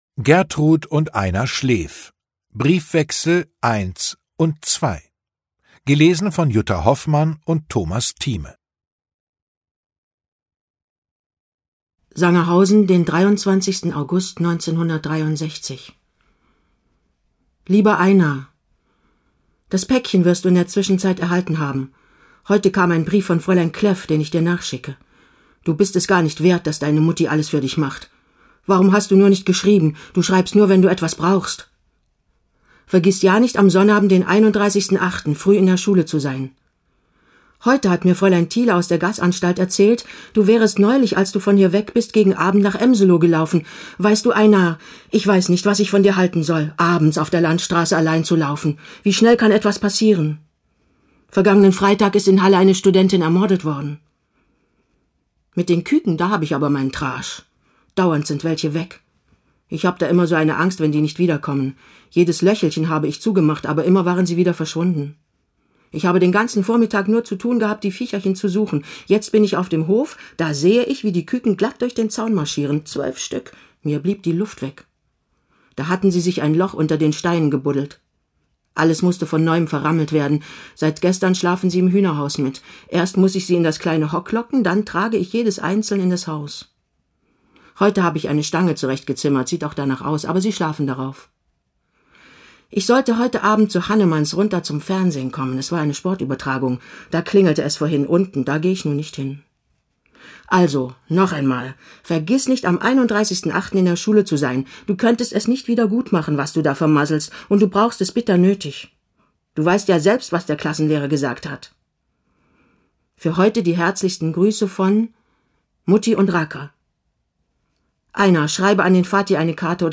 Lesung mit Jutta Hoffmann und Thomas Thieme (1 mp3-CD)
Jutta Hoffmann, Thomas Thieme (Sprecher)